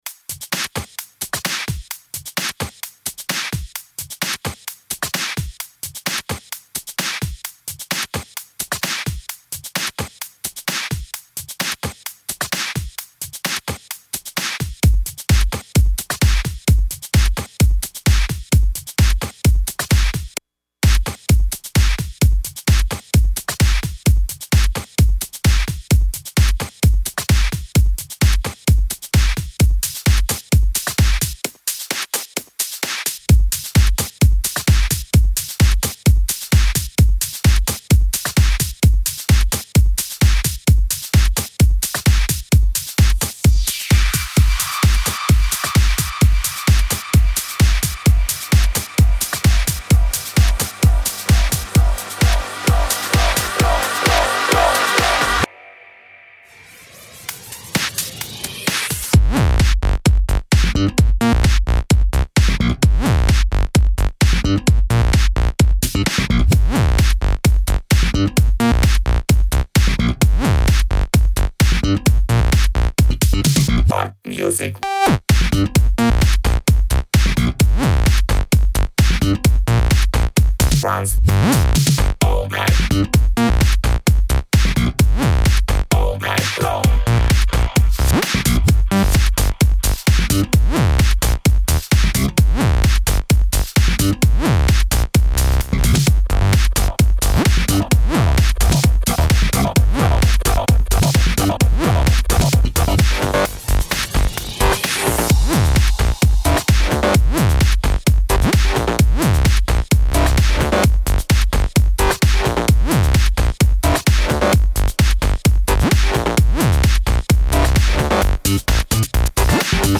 Fun mix I did the other day after weeks of inactivity.